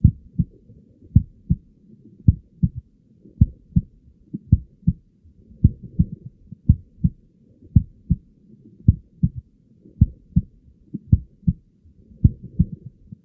Sfx_creature_seamonkeybaby_idle_heartbeat_loop_01.ogg